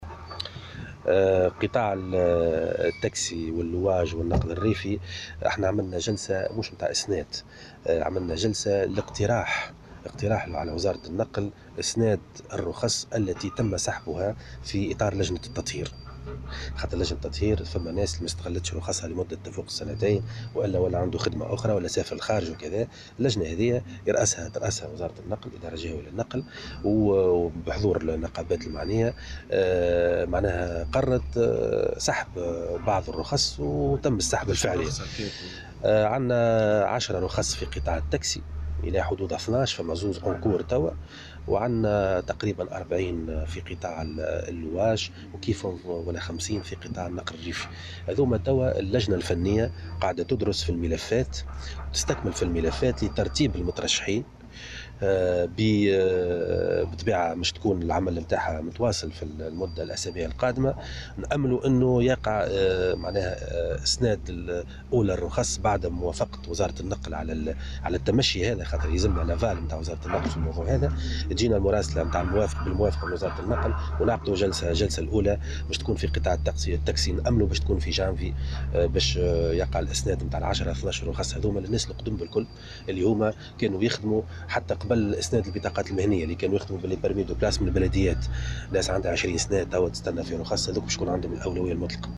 أكد والي المهدية محمد بودن، في تصريح لمراسل "الجوهرة أف أم" أنه تم التقدم بمقترح لوزارة النقل لإعادة إسناد رخص سيارات "تاكسي" و"لواج" غير مستغلة، تم سحبها في إطار لجنة التطهير.